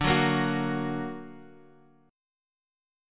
D+ chord {x 5 4 3 x 2} chord
D-Augmented-D-x,5,4,3,x,2.m4a